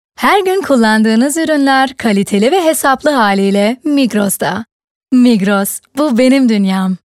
Sprecherin Synchron, Werbung, TV, Kino, Funk, Voice-Over, Höhrbuch, Hörspiel, Online-Games, Native Speaker Deutsch und Türkisch
Sprechprobe: Industrie (Muttersprache):